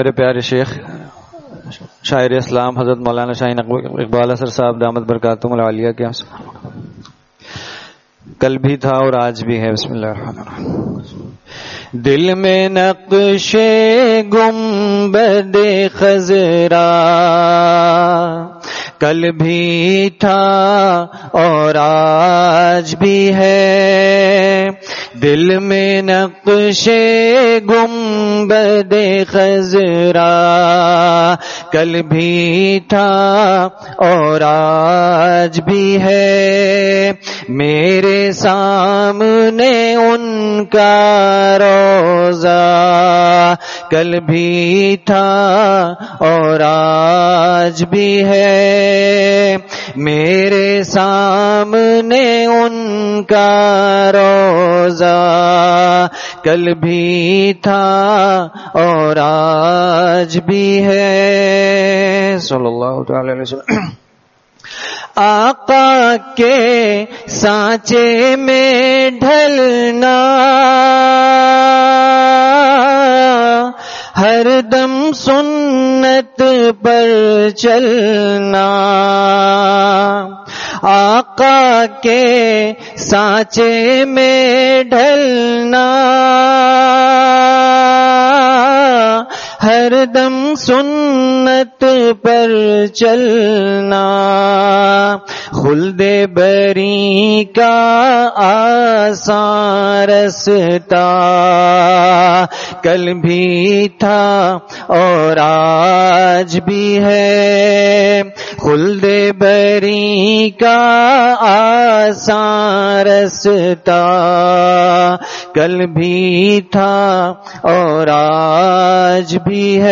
Saturday Markazi Bayan at Jama Masjid Gulzar e Muhammadi, Khanqah Gulzar e Akhter, Sec 4D, Surjani Town